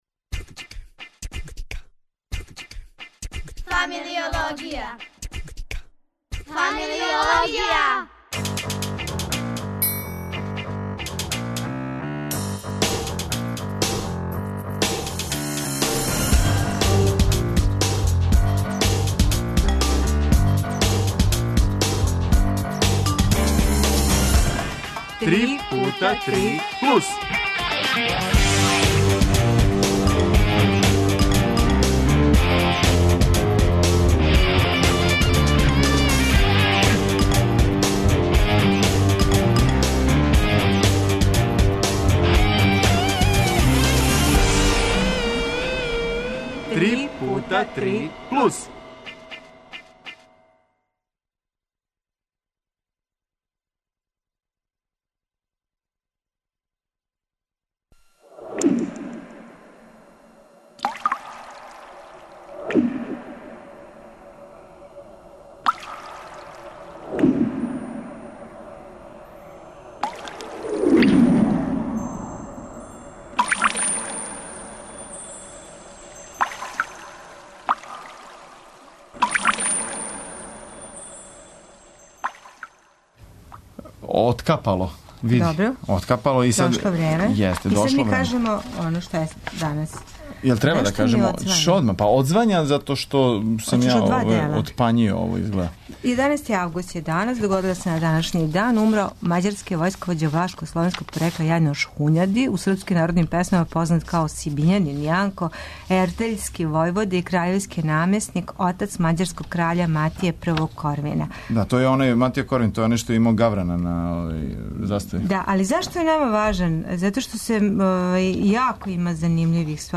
Програм за децу и младе Радио Београда 1